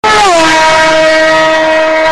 vrummmmmmmmmm